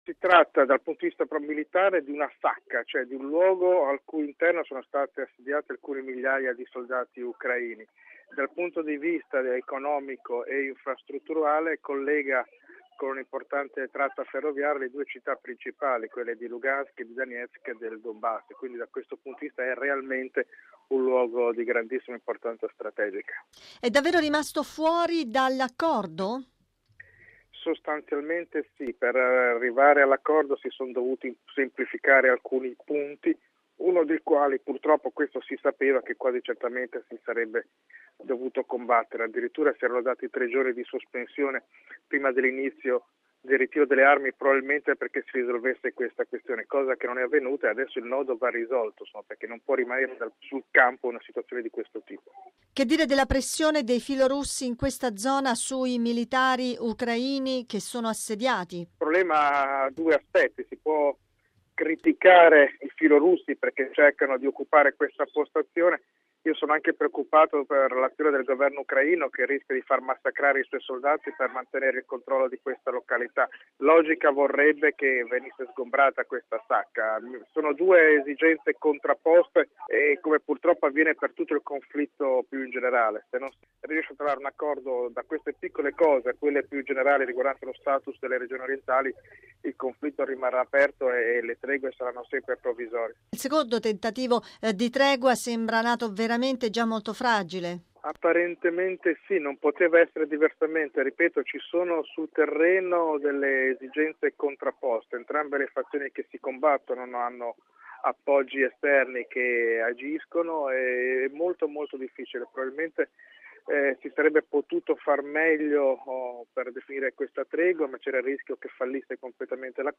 Bollettino Radiogiornale del 17/02/2015